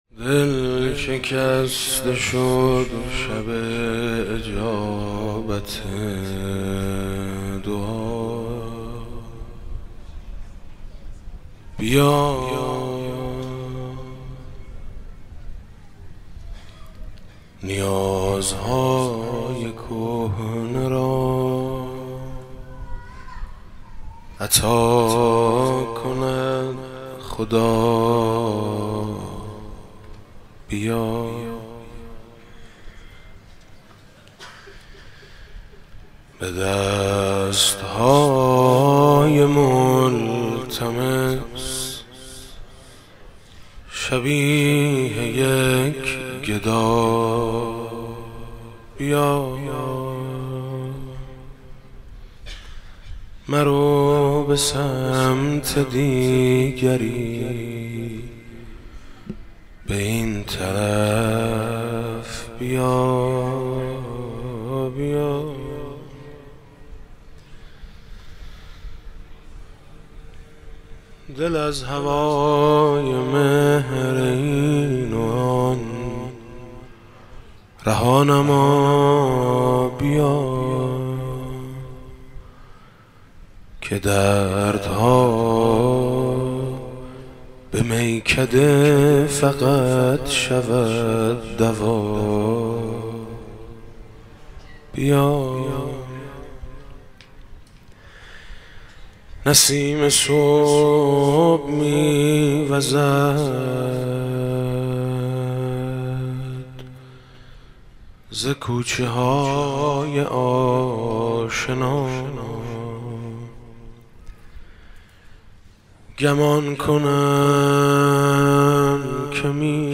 21 اردیبهشت 97 - مسجد شهدا - مناجات - سلام عدل منتظر